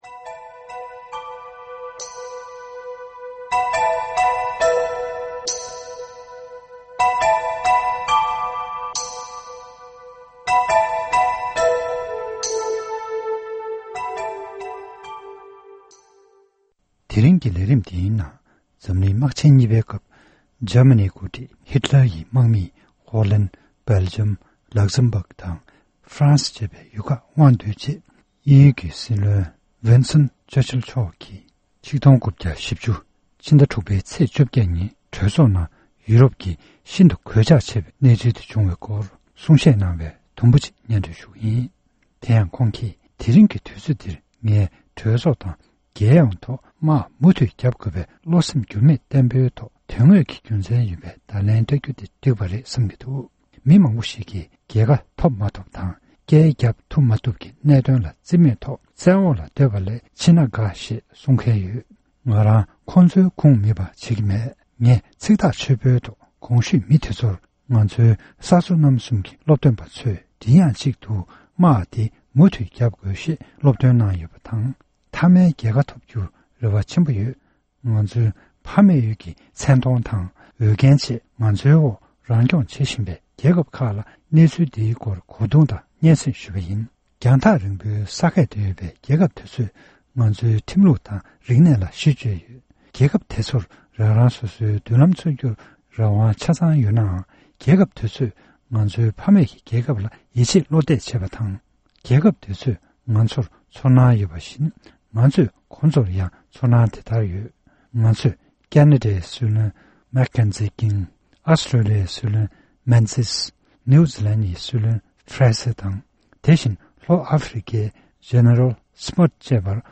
འཛམ་གླིང་དམག་ཆེན་གཉིས་པའི་སྐབས་དབྱིན་ཇིའི་སྲིད་བློན་མཆོག་ནས་གྲོས་ཚོགས་ནང་གནང་བའི་གསུང་བཤད།